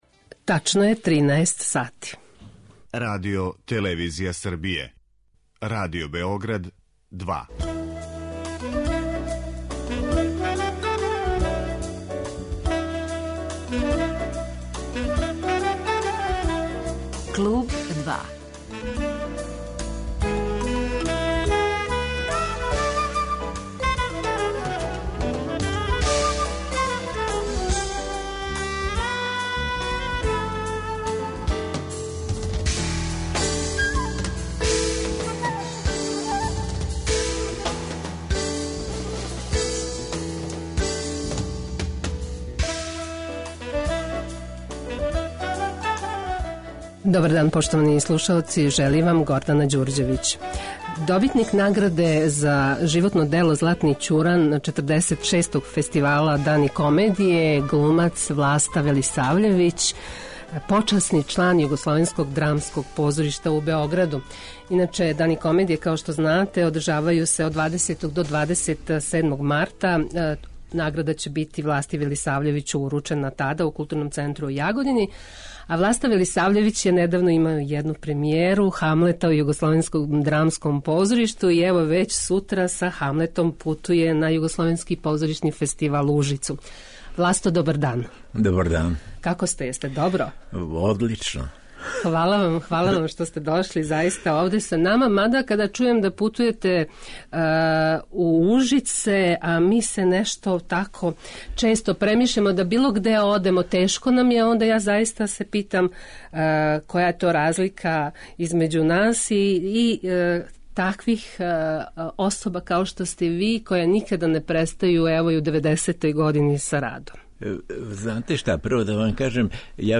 Гост данашњег 'Клубa 2' биће глумац Власта Велисављевић.